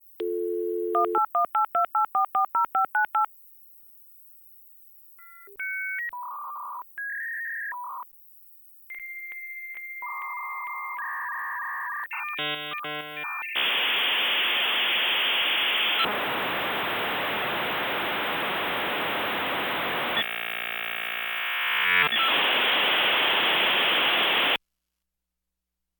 The sound of a modem connecting to the internet